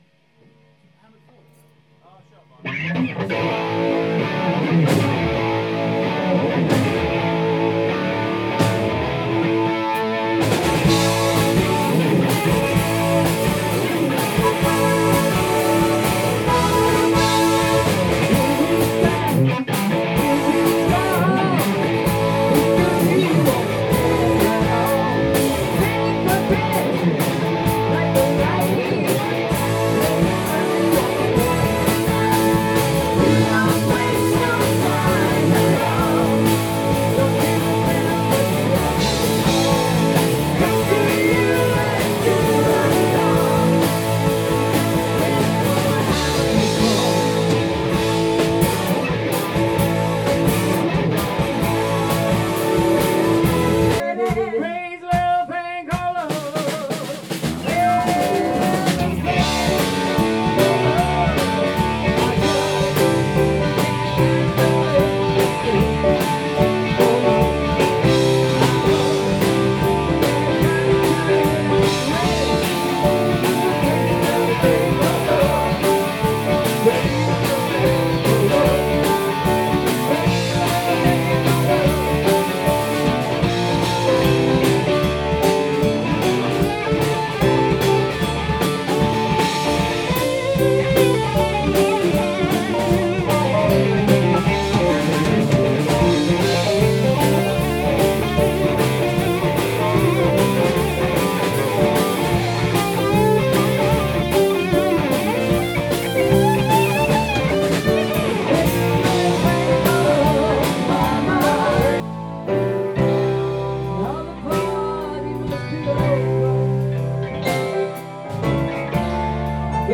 from rehearsal: